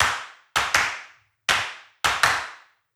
CLAP_RESTCL.wav